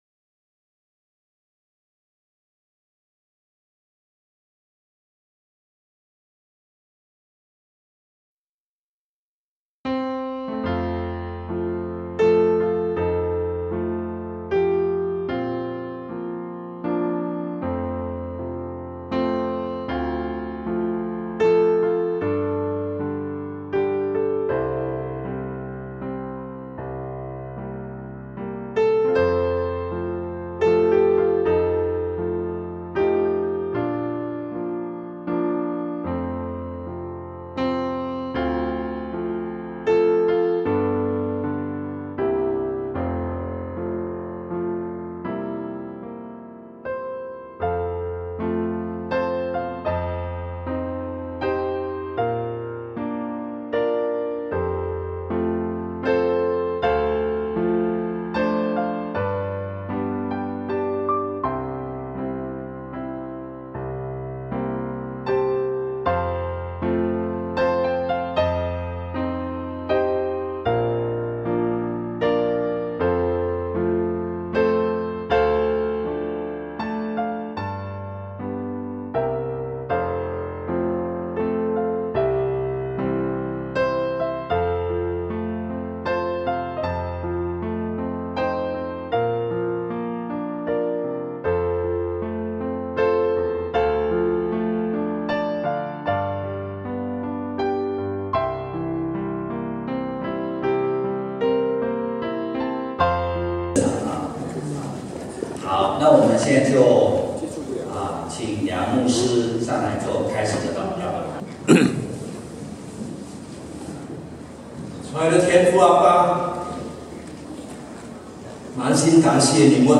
中文堂2017年感恩見證會 – Raleigh Chinese Christian Church